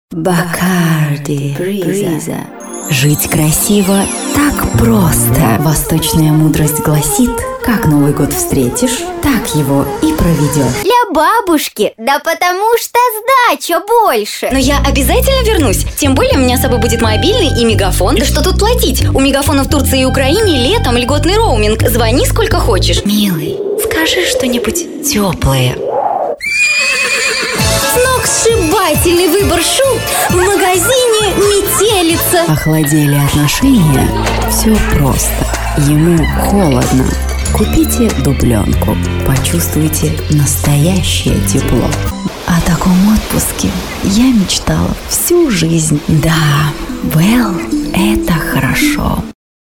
Диапазон: мальчики и девочки разного возраста, девушки, женщины, бабушки, сказочные, мультяшные персонажи.
микрофоны: beyerdynamic opus81 предусилители: behringer mic200 другое оборудование: звуковая карта line6 gear box компрессор dbx 266xl